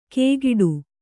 ♪ kēgiḍu